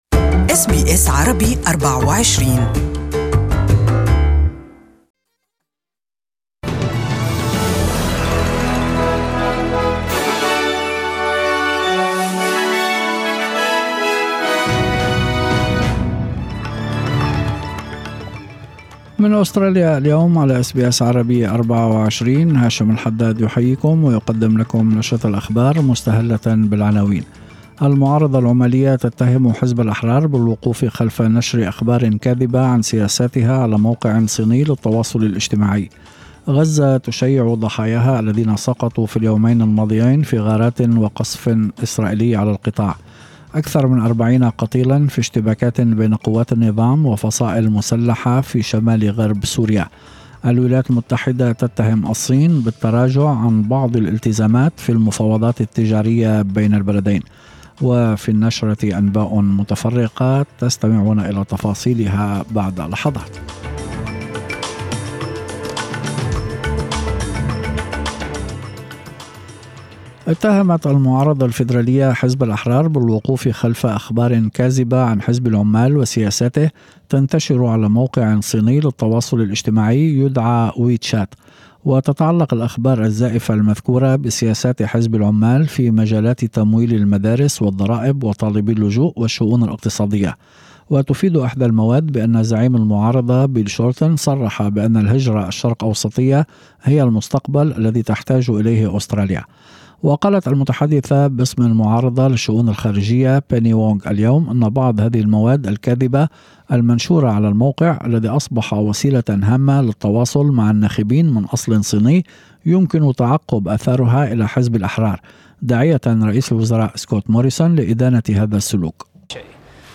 The 4pm news bulletin